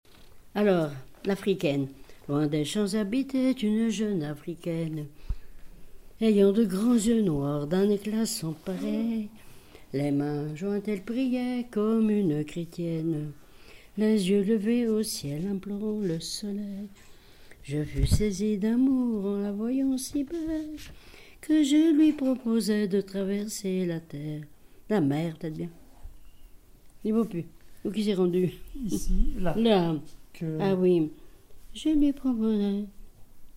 Saint-Valérien
Genre laisse
Pièce musicale inédite